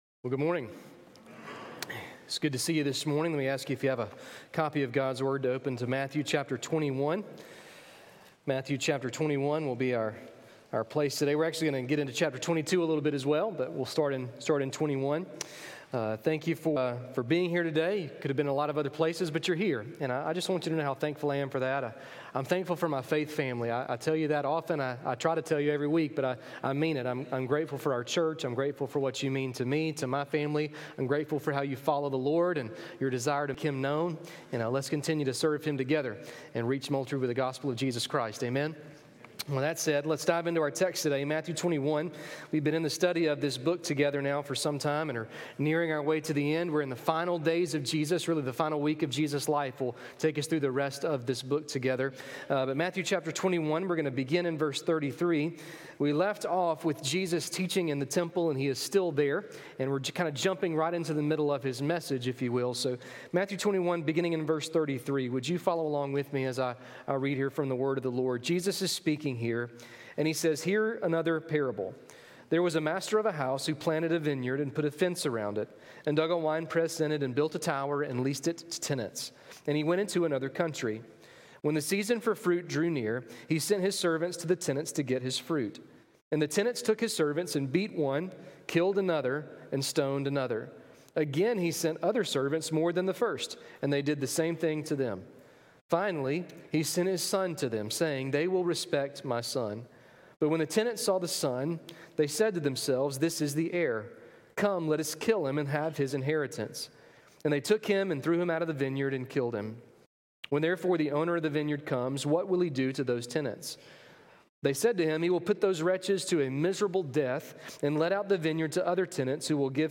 A message from the series "Your Kingdom Come."